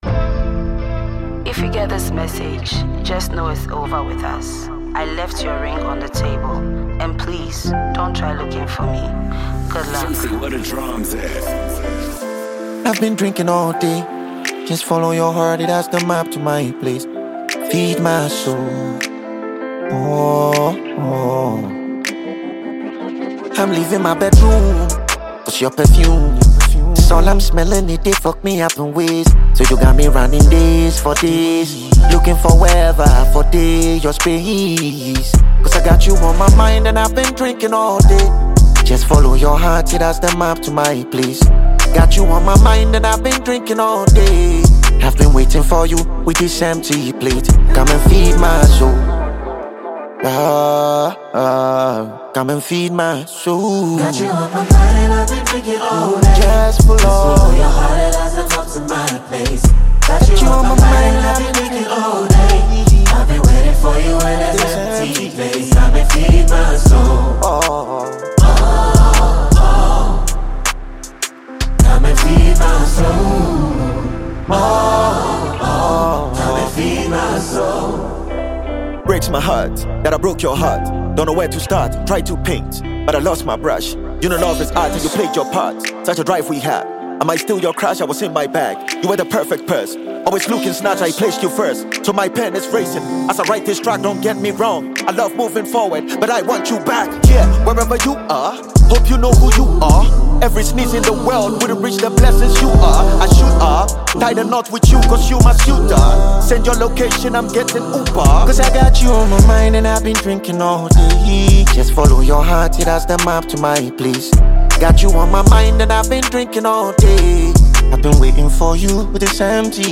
Ghanaian rap lyricist
introspective and soul-stirring track